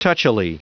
Prononciation du mot touchily en anglais (fichier audio)
Prononciation du mot : touchily